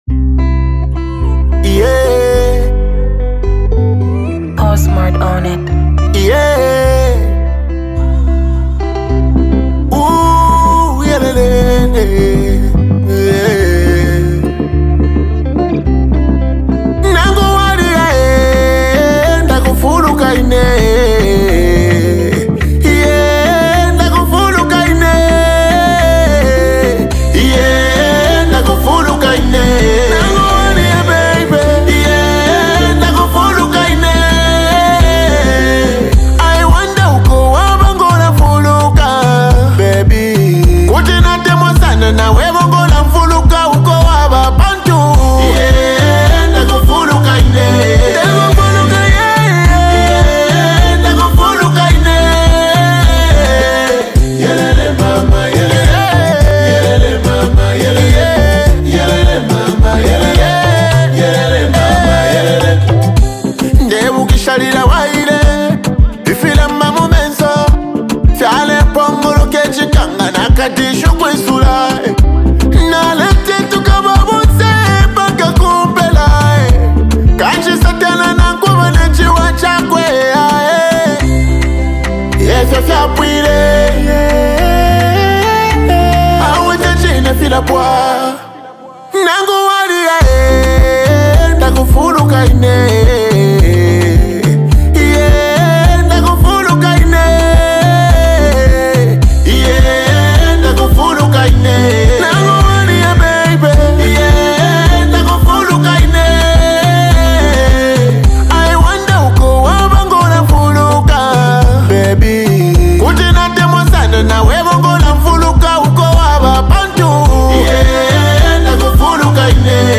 fusing catchy melodies with rhythmic beats.
infectious rhythm